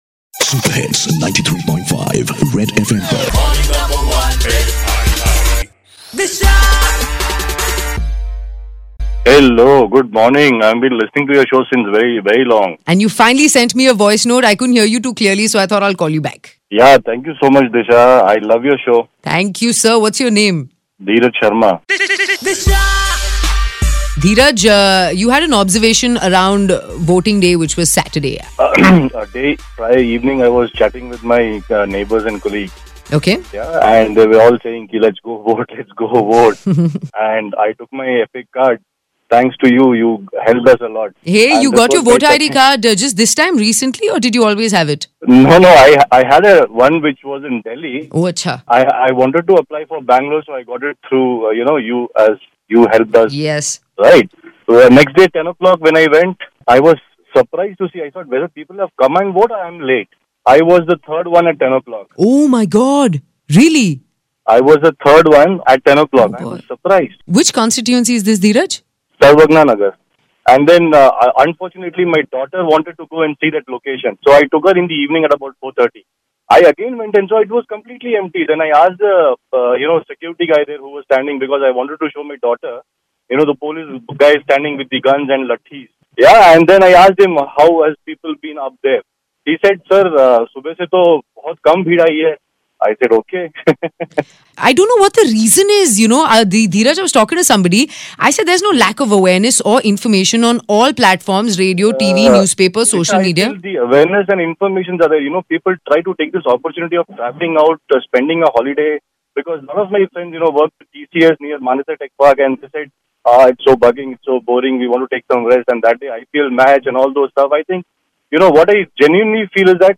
A caller who talks about his experience at polling station on Saturday